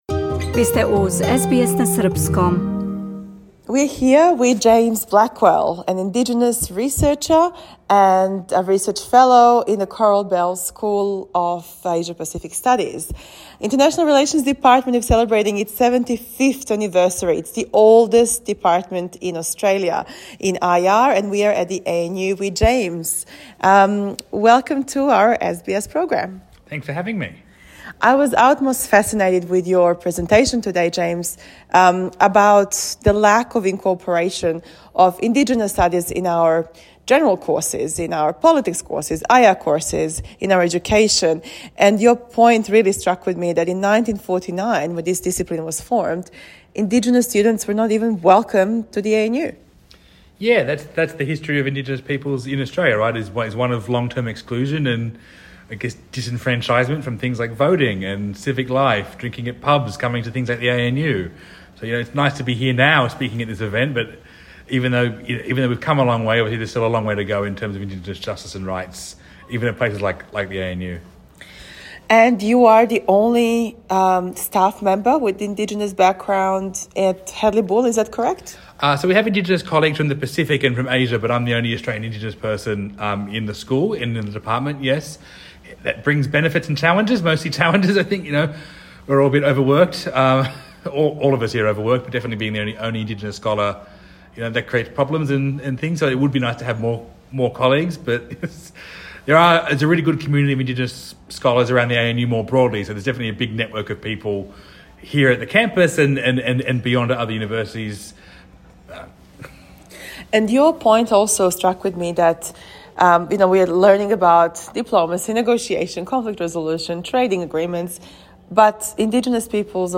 Овај интевју је на енглеском језику.